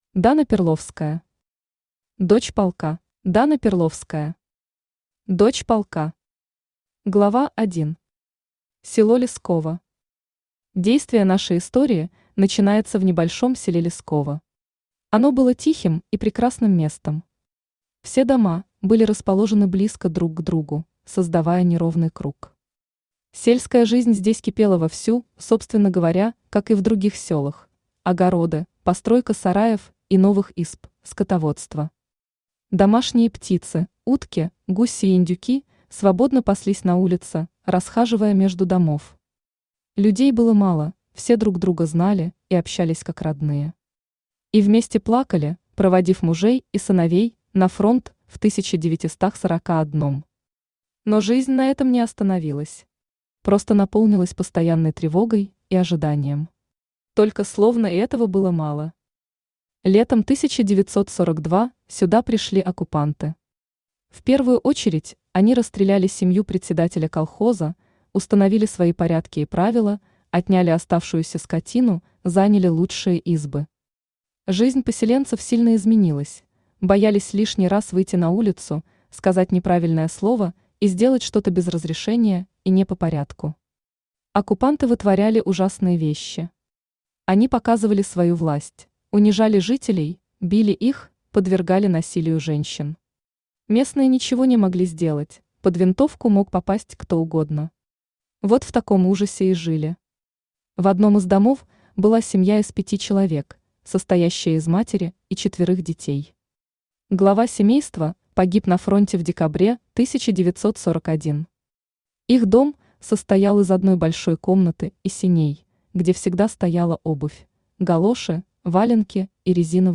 Aудиокнига Дочь полка Автор Дана Перловская Читает аудиокнигу Авточтец ЛитРес.